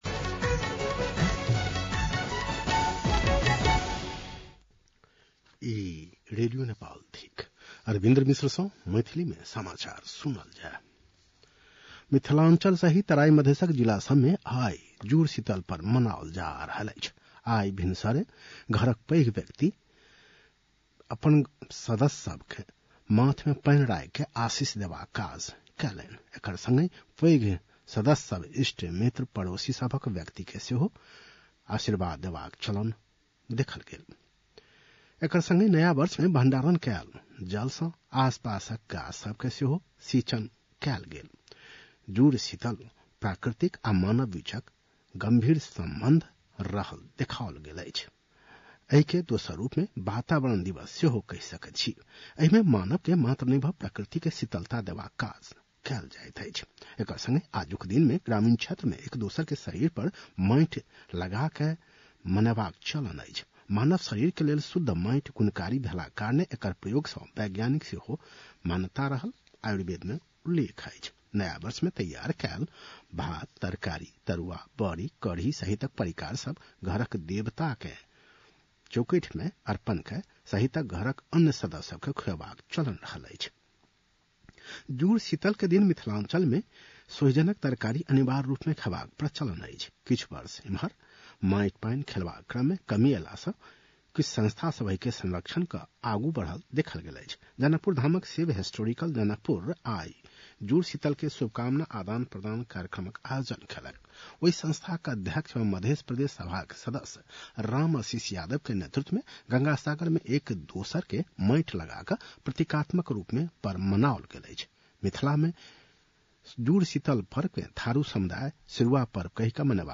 मैथिली भाषामा समाचार : २ वैशाख , २०८२